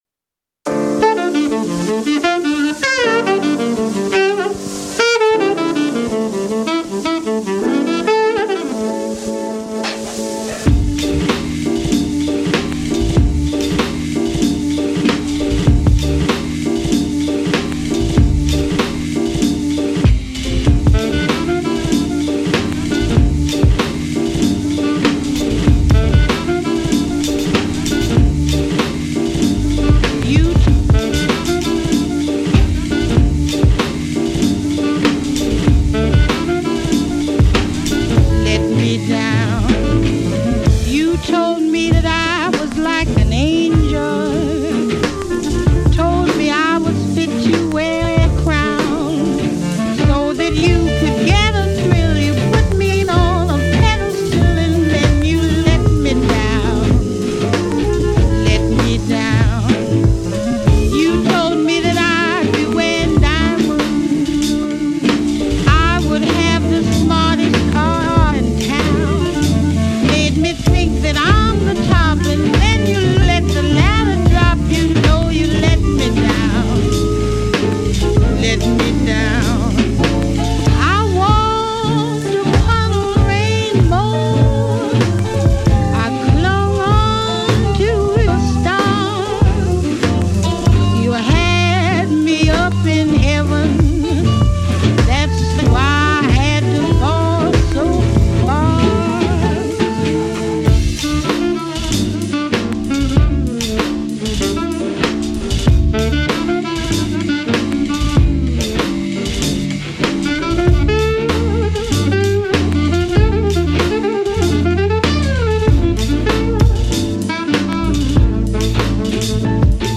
Swing/Jazz orientierten HipHop Mix